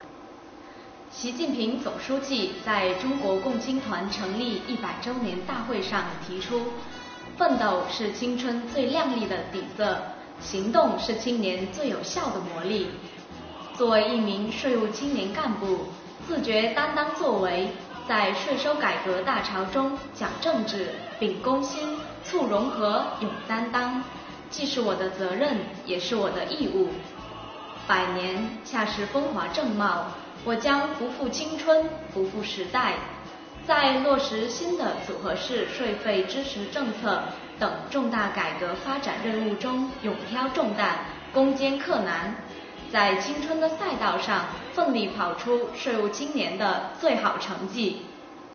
建团百年，听听来宾税务青年的心里话......